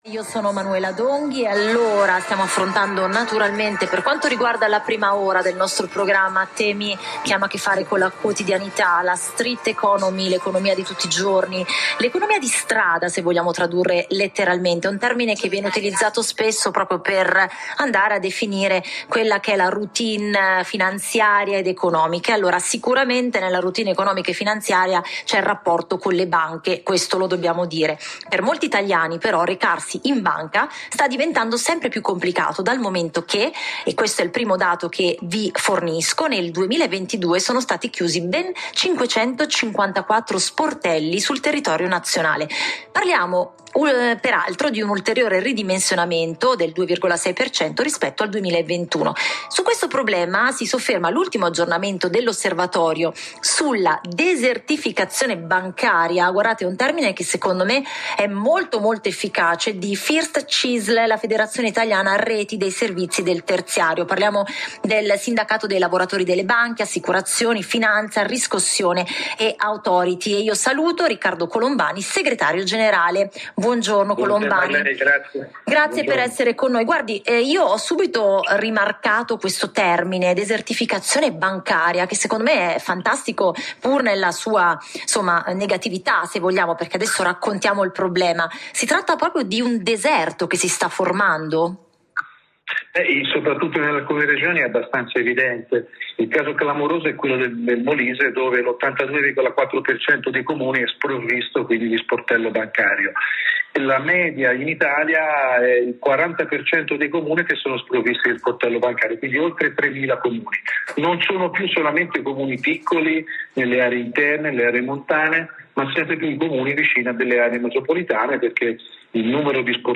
a Giornale Radio, nel corso della trasmissione Next Economy